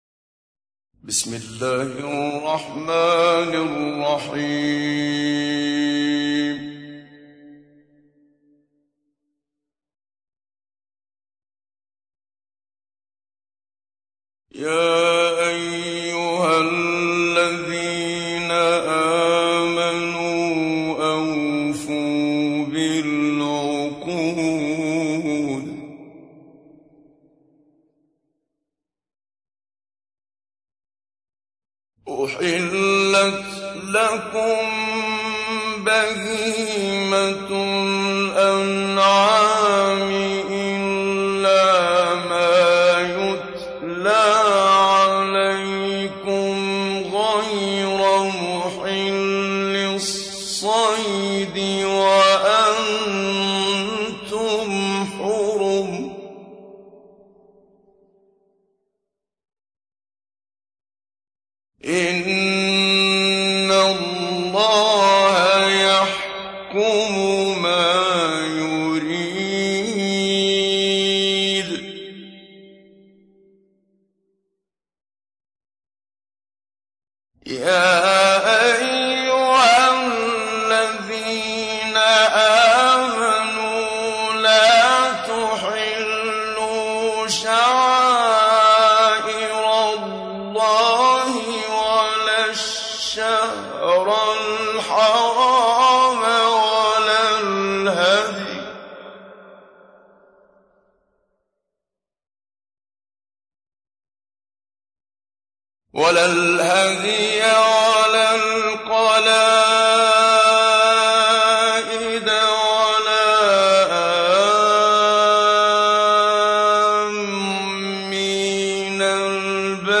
تحميل : 5. سورة المائدة / القارئ محمد صديق المنشاوي / القرآن الكريم / موقع يا حسين